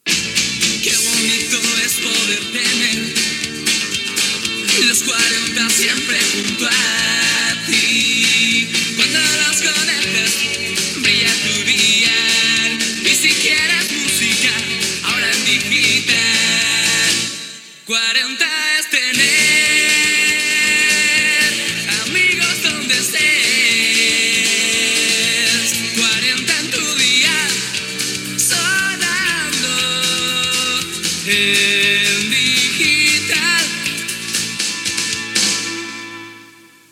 Indicatiu de la candena
FM